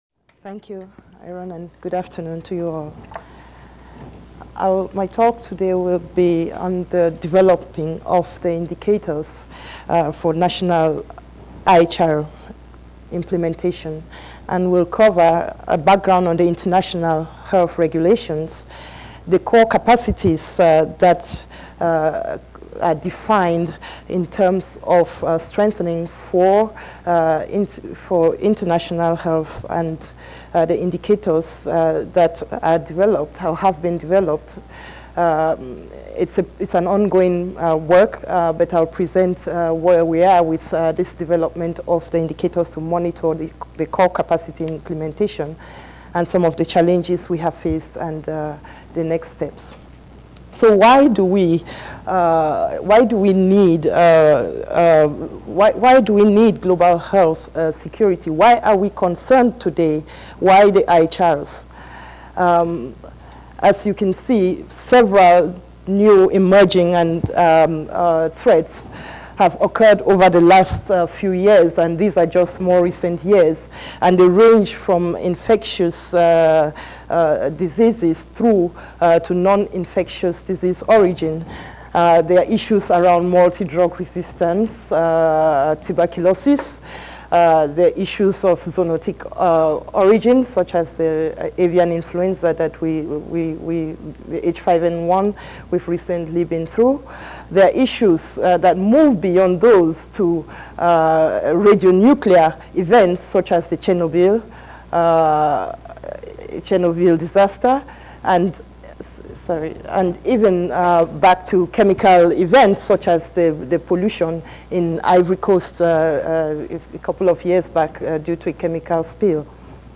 Centers for Disease Control and Prevention Audio File Slides Recorded presentation